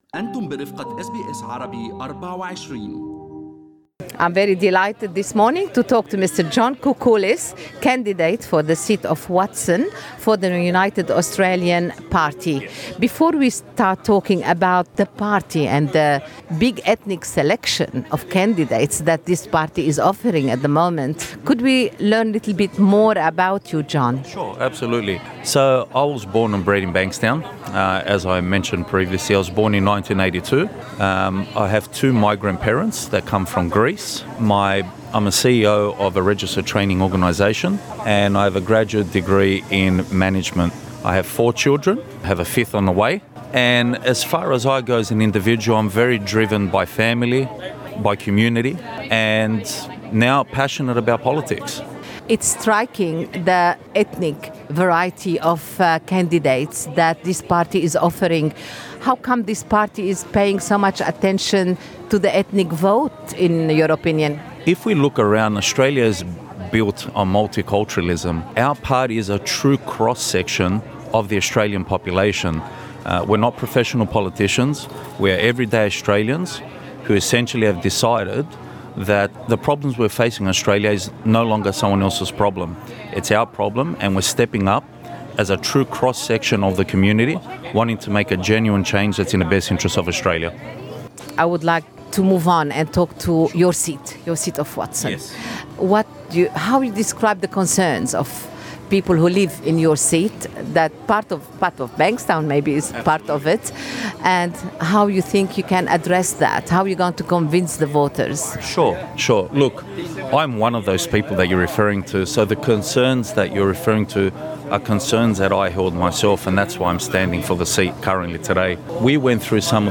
من ساحة سايغون باي بمنطقة بانكستاون بغرب سيدني، تواجدت أس بي أس عربي24 للحديث عن الانتخابات الفدرالية مع المرشحين عن دائرة Watson ولمعرفة آراء وطموحات الناخبين خلال الفترة المقبلة.